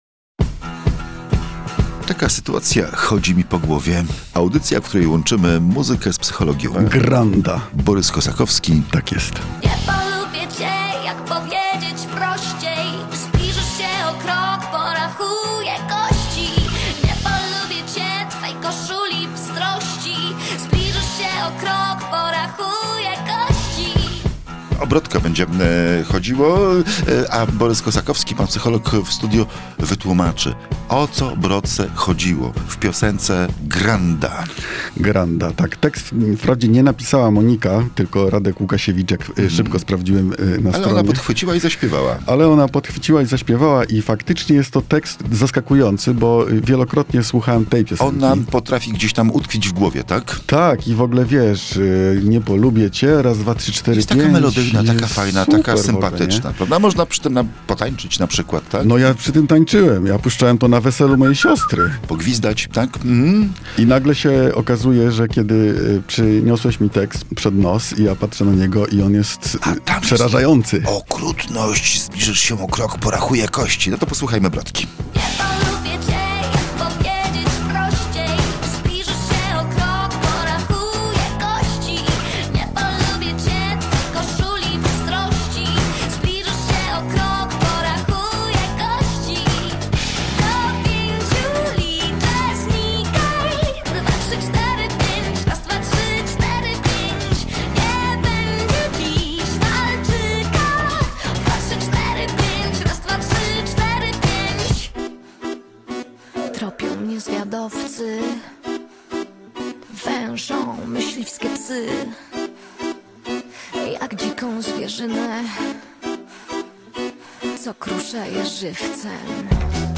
Tekst jest pretekstem do rozmowy o psychopatach, ludziach którzy pozbawieni są empatii, bez problemu zakłamują rzeczywistość, manipulują, usidlają.